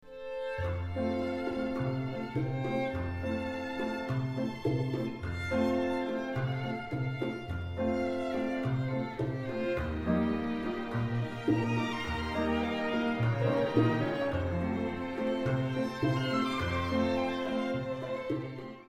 ライブ録音ですので多少のノイズがございます。
ラテン